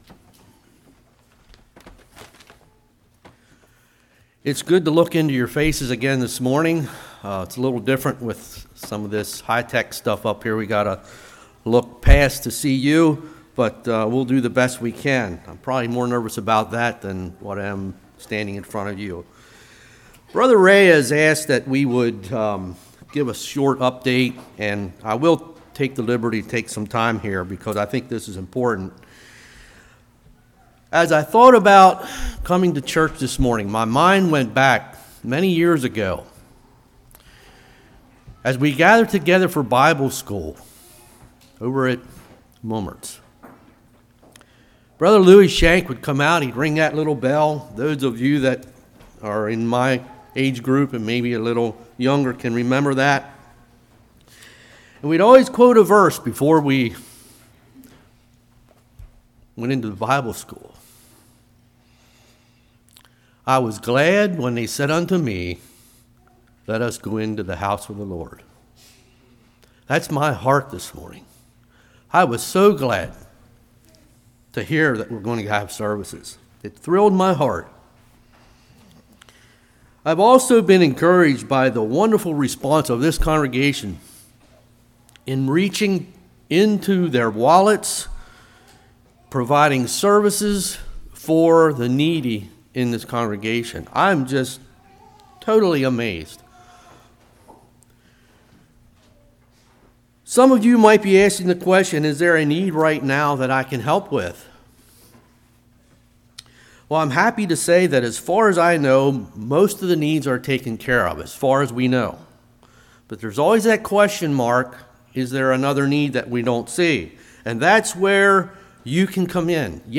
1 Peter 1:17-25 Service Type: Morning Redemption defined What are we redeemed from?